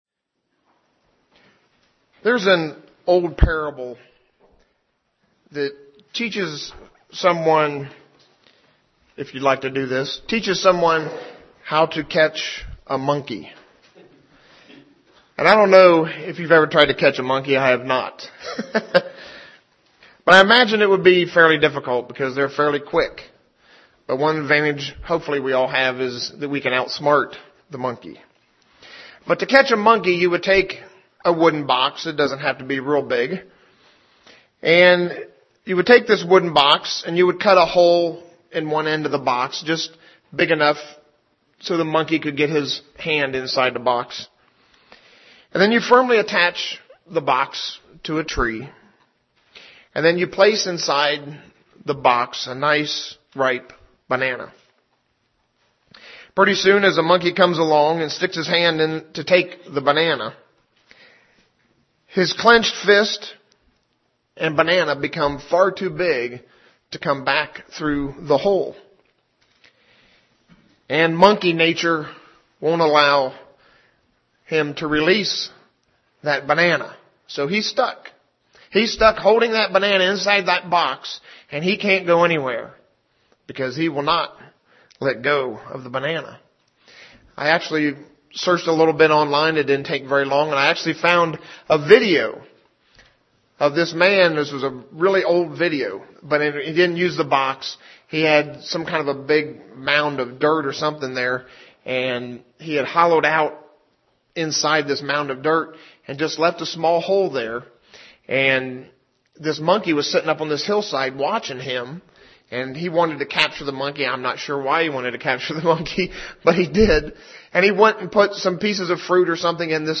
UCG Sermon Notes Notes: Sometimes we experience things that can cause us great harm, something that we are unwilling to let go.